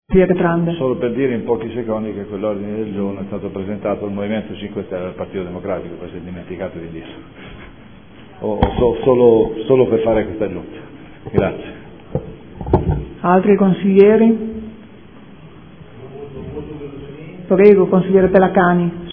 Paolo Trande — Sito Audio Consiglio Comunale
Seduta del 31/07/2014.